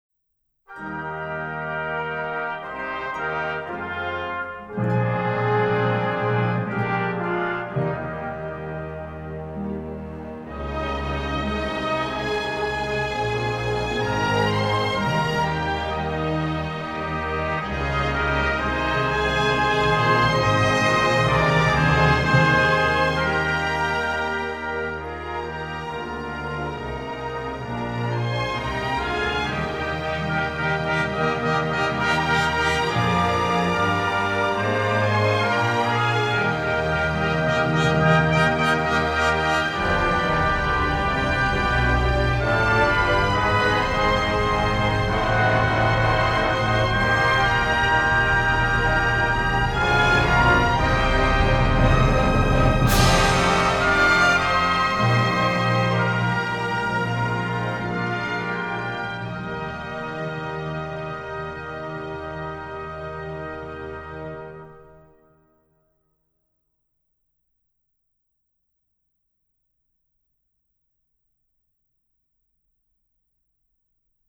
Orchestr Národního divadla - Kde domov můj?
Zvukový záznam nahrávky české státní hymny v podání Orchestru Národního divadla